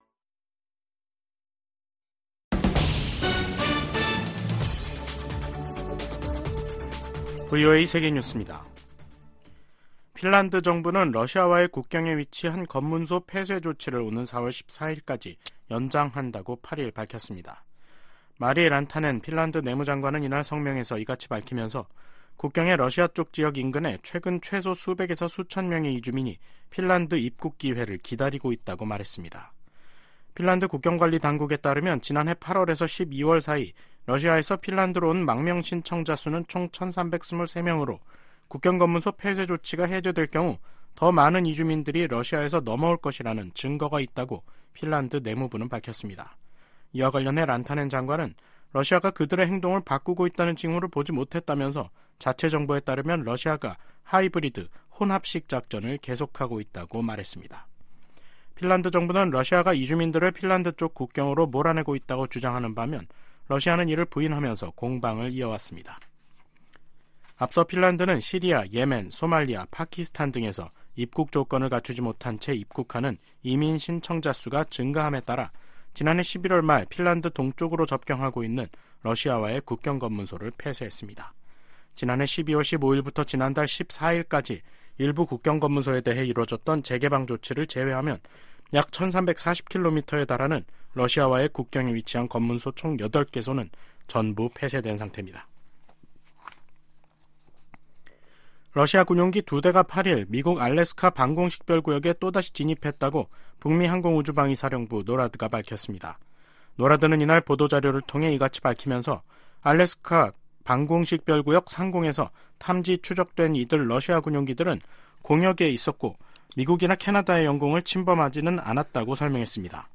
VOA 한국어 간판 뉴스 프로그램 '뉴스 투데이', 2024년 2월 9일 3부 방송입니다. 김정은 북한 국무위원장이 조선인민군 창건일인 8일 건군절 국방성 연설에서 한국을 제1 적대국가로 규정했다고 노동신문이 보도했습니다. 미 국무부는 북한 7차 핵실험 가능성을 경고한 주북 러시아 대사의 발언을 불안정하고 위태로우며 위험한 언행이라고 비판했습니다. 일부 전문가들이 한반도 전쟁 위기설을 제기한 가운데 미 국방부는 북한의 임박한 공격 징후는 없다고 밝혔습니다.